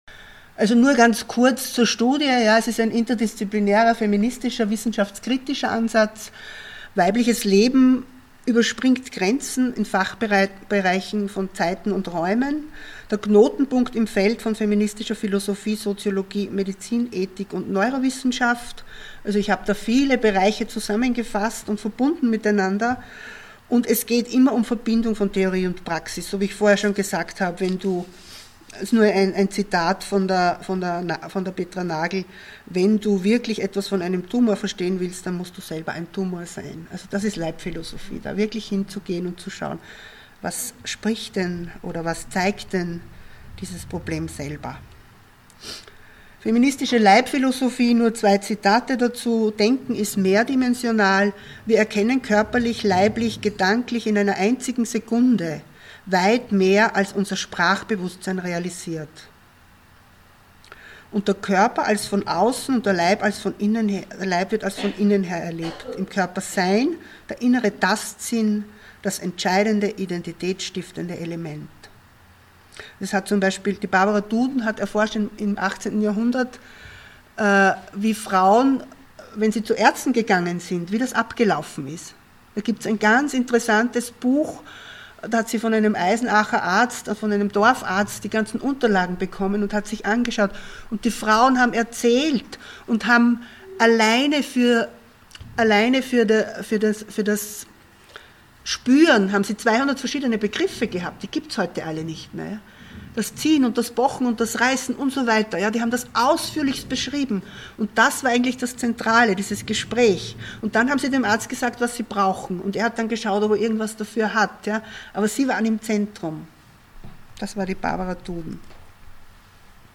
Vortrag
auf dem LACHESIS Kongress 2017